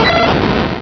Cri de Raikou dans Pokémon Rubis et Saphir.